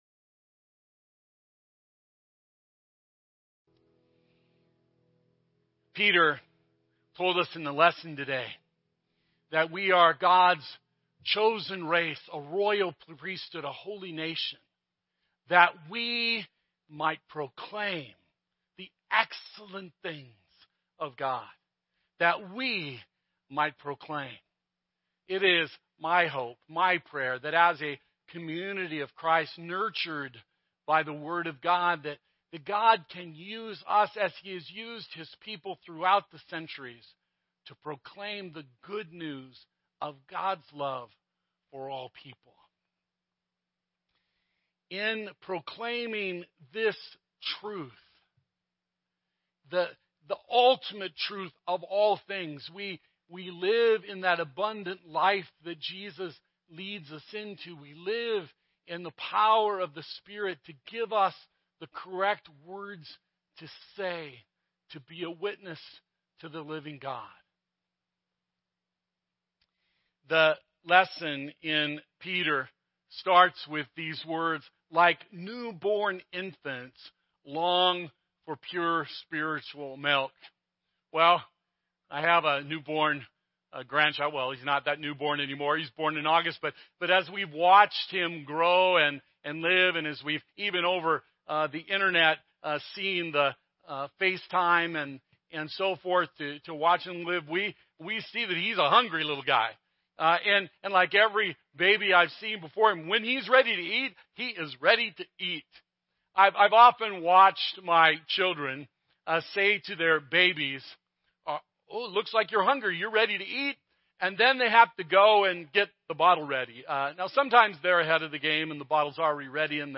Sermons – Desert Hope Lutheran Church